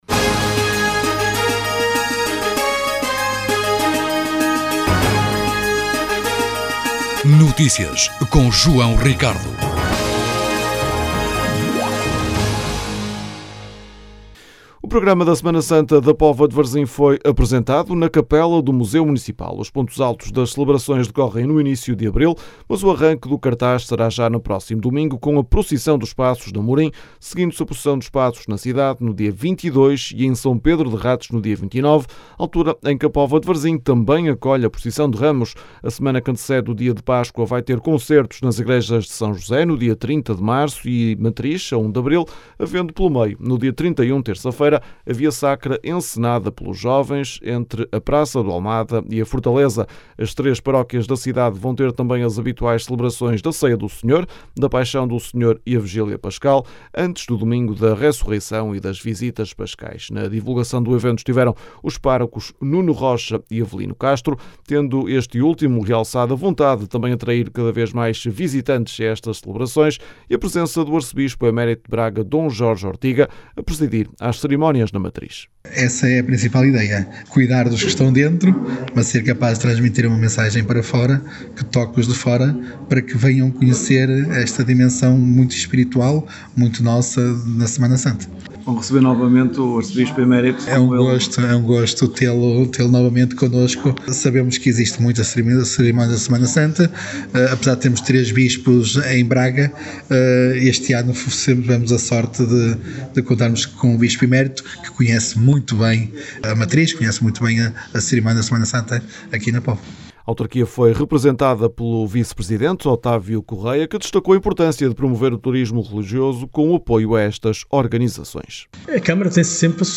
O programa da Semana Santa na Póvoa de Varzim foi apresentado na Capela do Museu Municipal.
As declarações podem ser ouvidas na edição local.